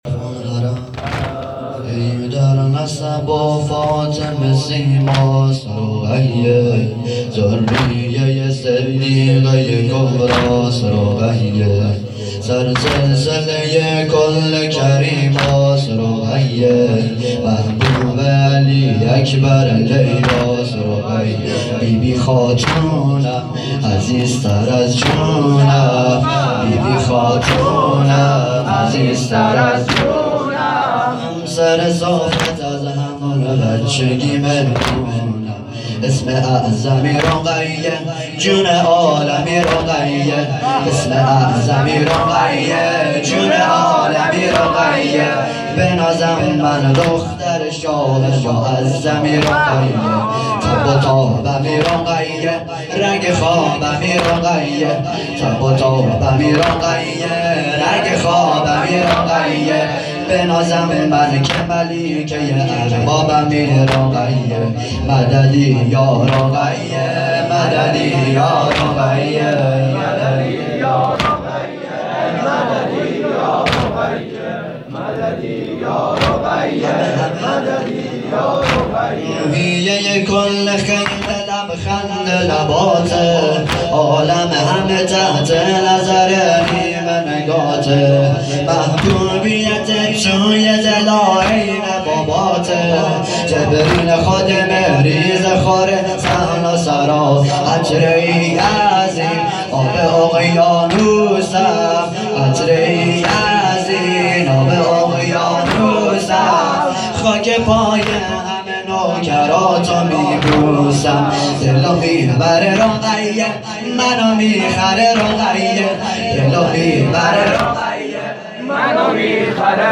توضیحات: هیئت صادقیون حوزه علمیه زابل